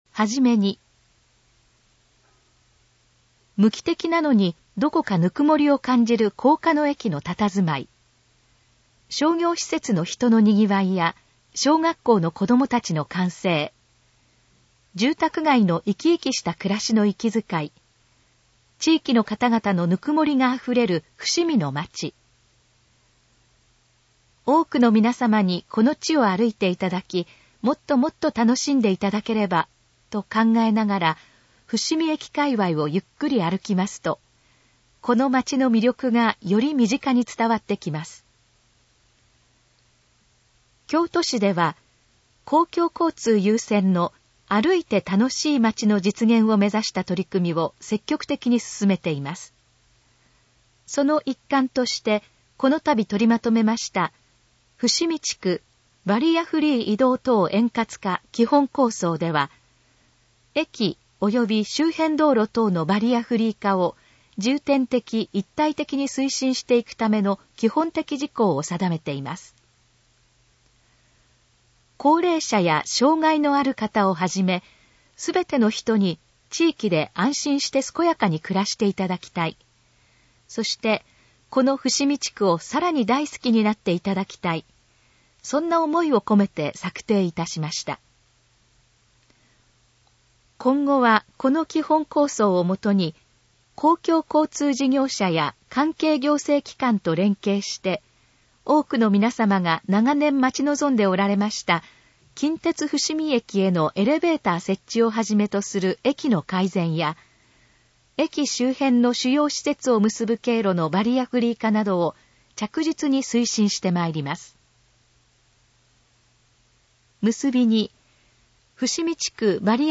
このページの要約を音声で読み上げます。
ナレーション再生 約570KB